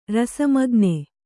♪ rasa magne